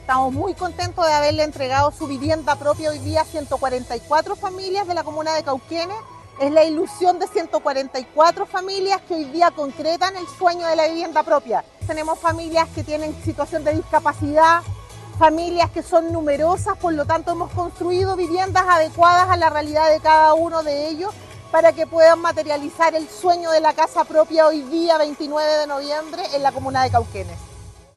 En una emotiva ceremonia, 144 familias de Villa Ilusión, en Cauquenes, recibieron las llaves de sus nuevos hogares.
Por su parte, la directora de SERVIU Maule, Paula Oliva, subrayó la importancia de Villa Ilusión como un espacio diseñado para construir comunidad.